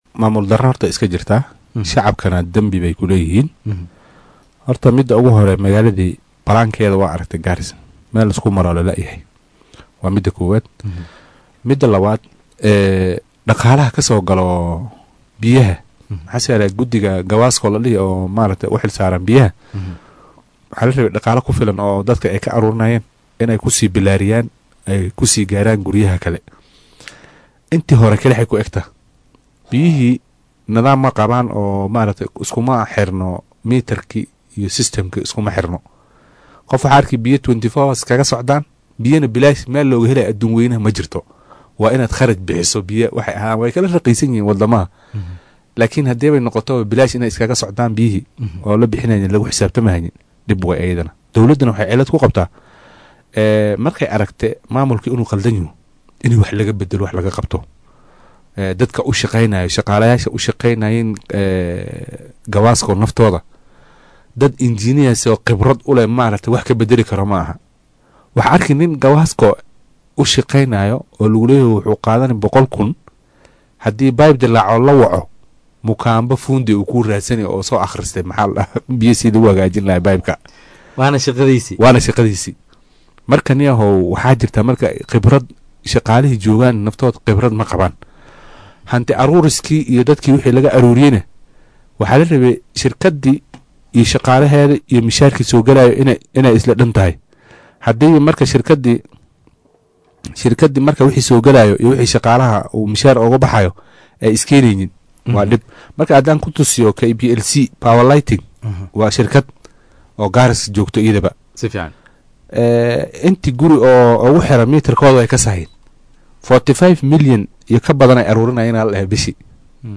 Arrimahan ayuu ka hadlay xilli uu saaka marti ku ahaa Barnaamijka Hoggaanka Star ee arrimaha dalka.